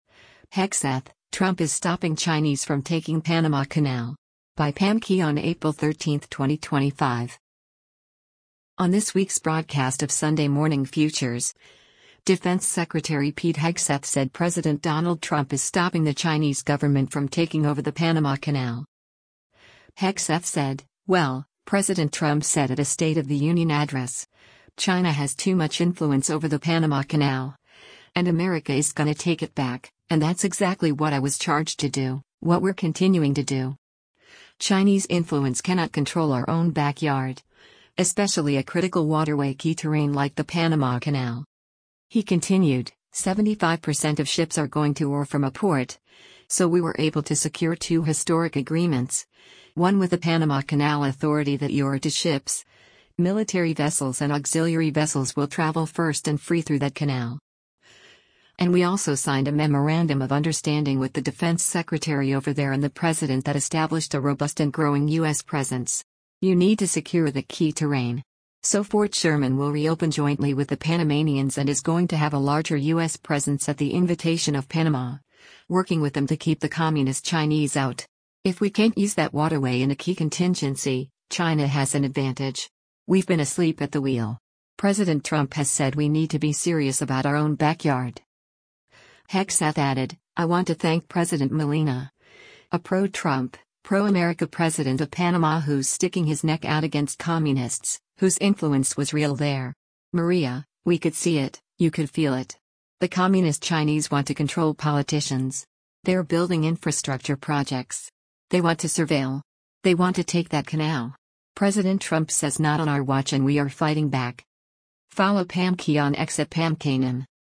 On this week’s broadcast of “Sunday Morning Futures,” Defense Secretary Pete Hegseth said President Donald Trump is stopping the Chinese government from taking over the Panama Canal.